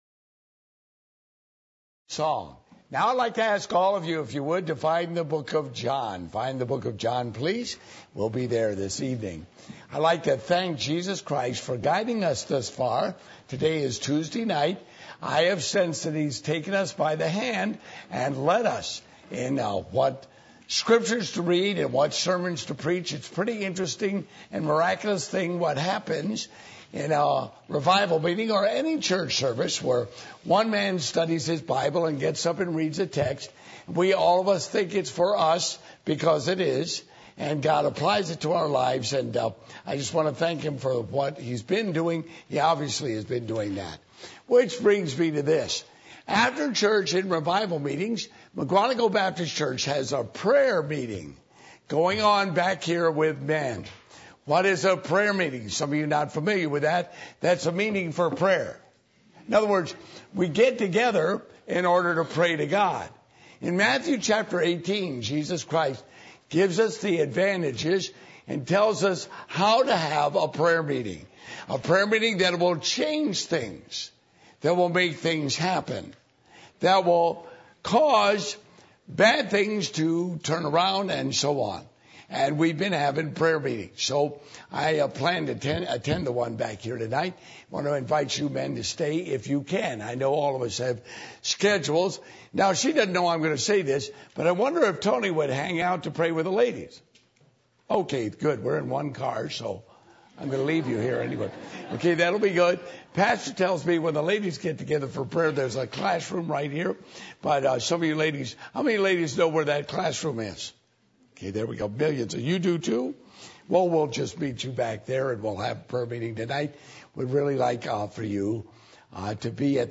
Passage: John 4:1-14 Service Type: Revival Meetings